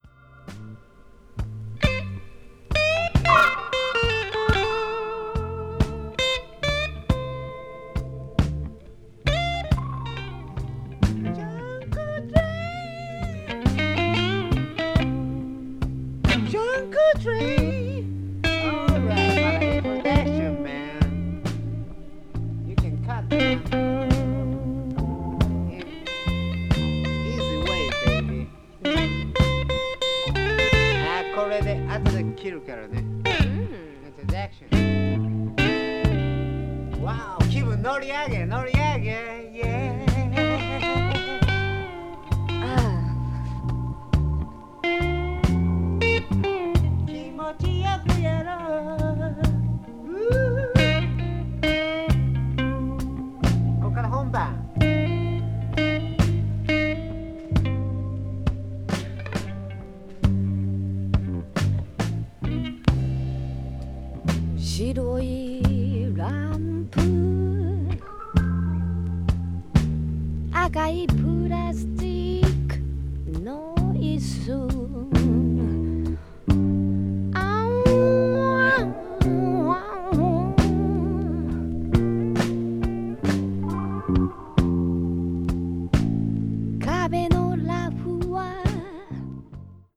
media : EX/EX(わずかにチリノイズが入る箇所あり)
blues rock   classic rock   funky rock   rare groove